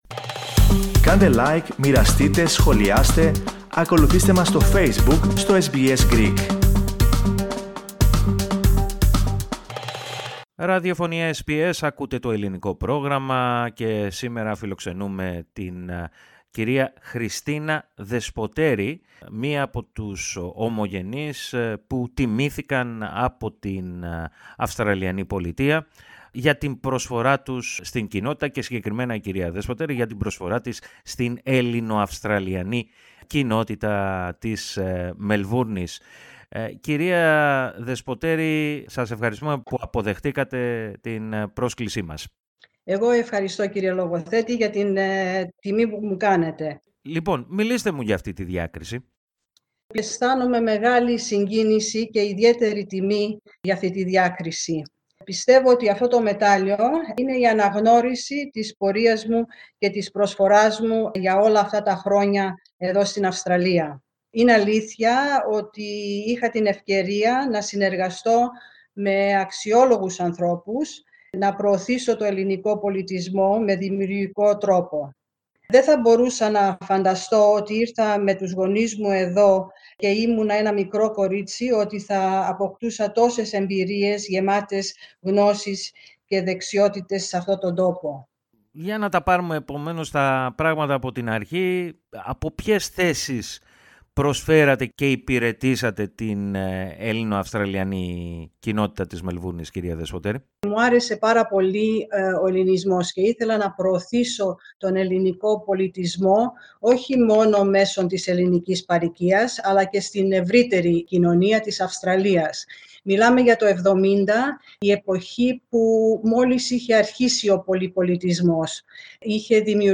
Ακούστε, ολόκληρη τη συνέντευξη πατώντας το σύμβολο στο μέσο της κεντρικής φωτογραφίας.